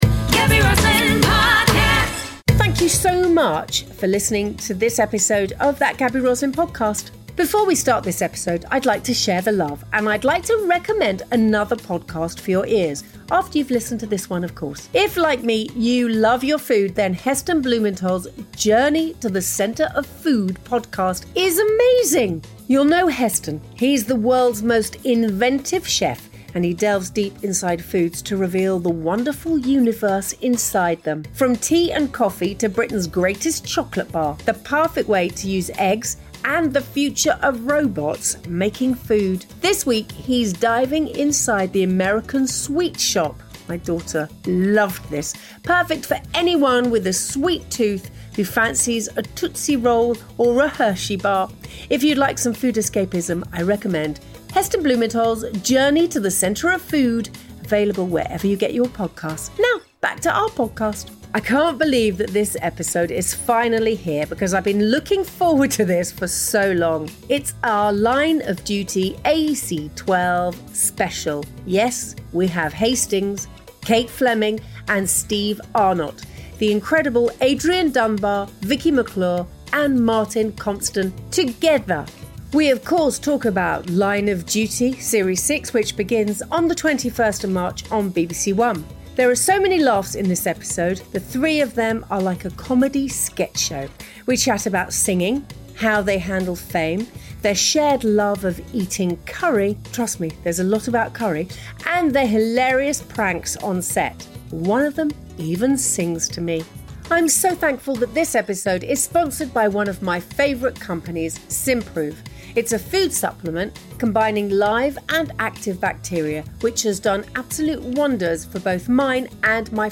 The audio in this interview was recorded on Zoom video call so it sounds slightly different to the other episodes.